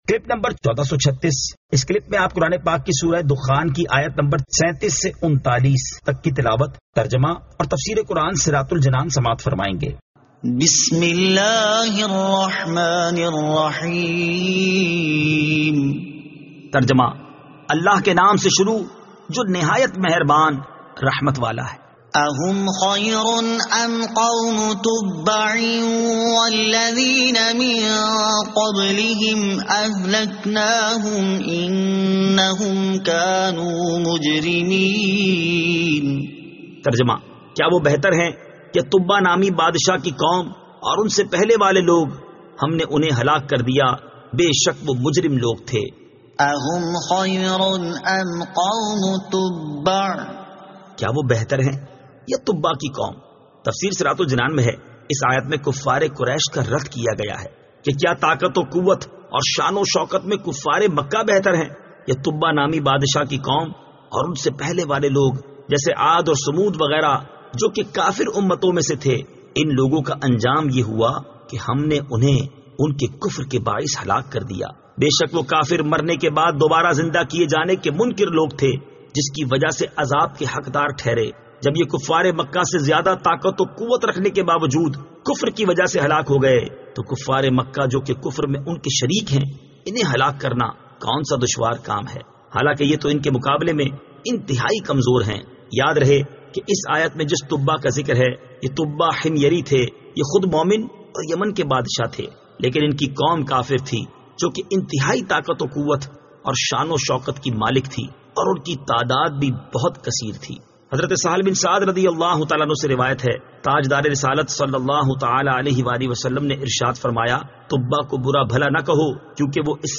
Surah Ad-Dukhan 37 To 39 Tilawat , Tarjama , Tafseer